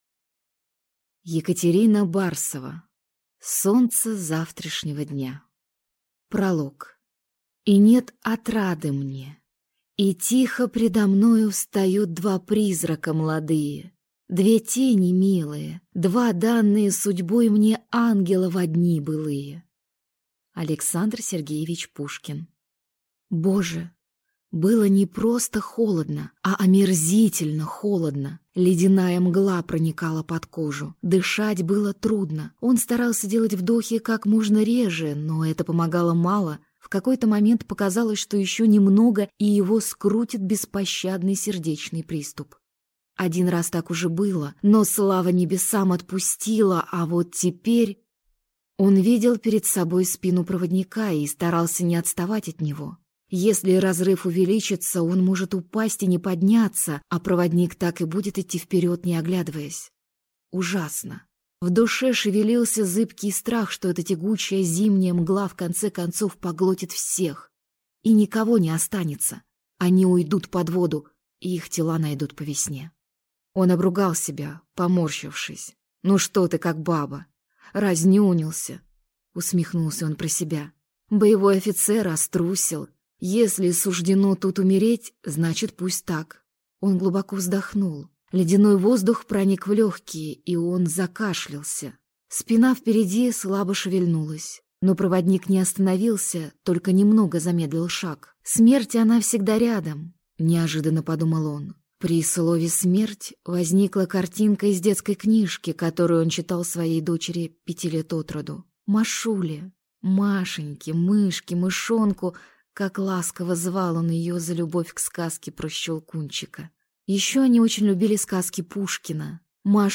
Аудиокнига Солнце завтрашнего дня | Библиотека аудиокниг